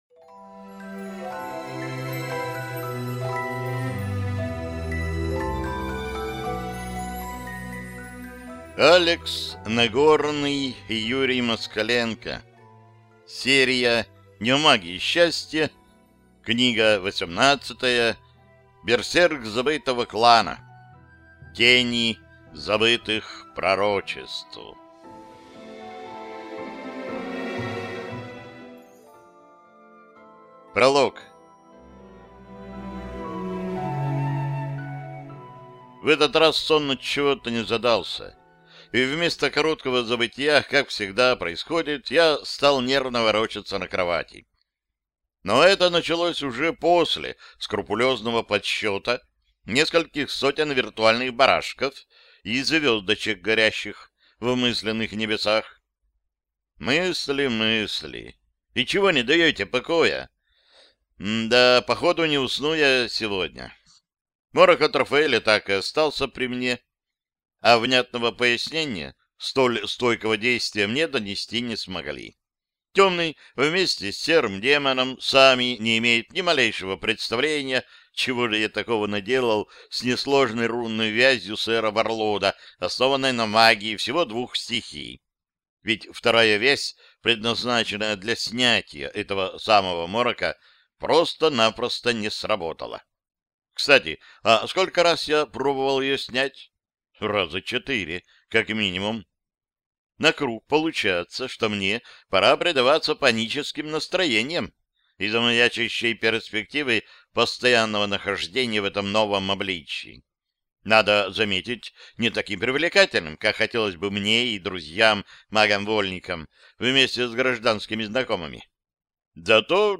Аудиокнига Берсерк забытого клана. Тени забытых пророчеств | Библиотека аудиокниг